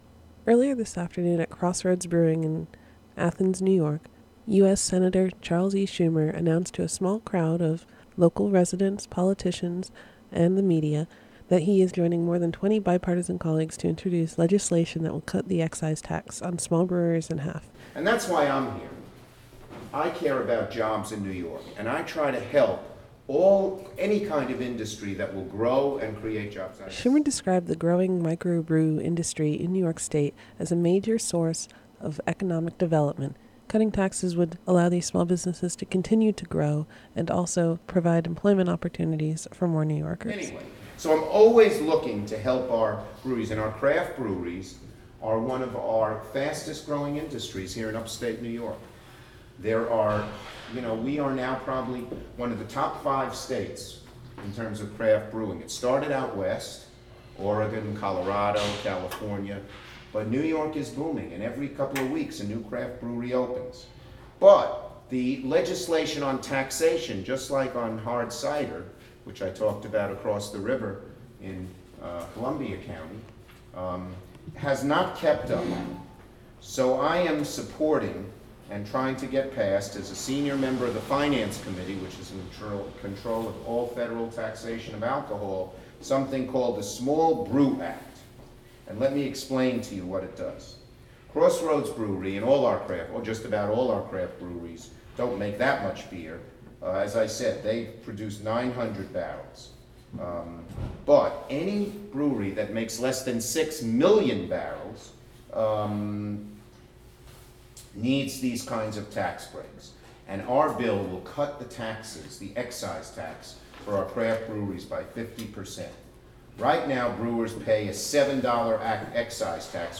Sen. Charles Schumer (D-Brooklyn) talked at, and toured, the Crossroads Brewery in Athens Nov. 1. (Audio)